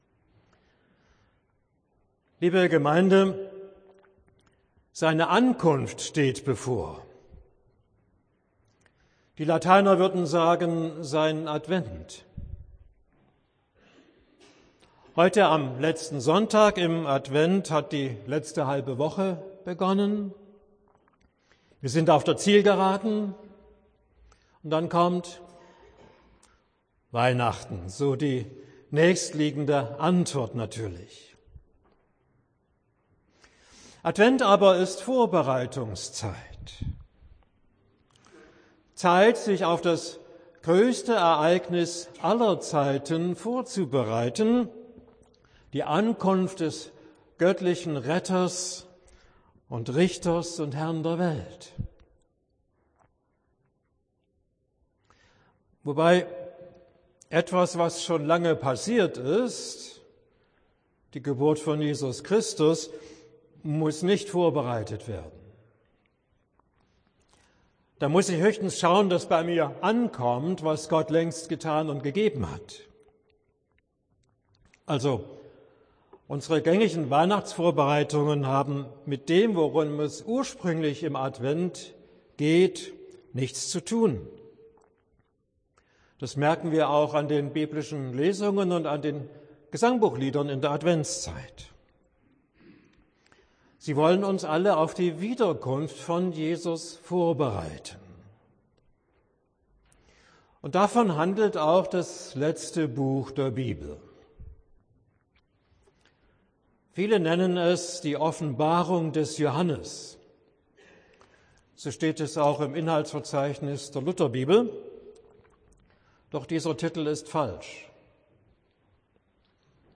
Predigt am 4. Adventssonntag